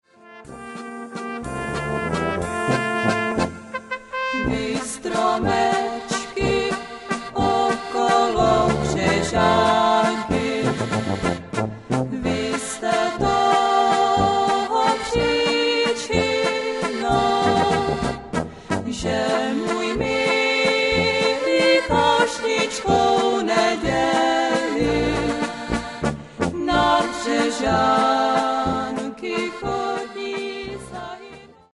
Mal� dechov� hudba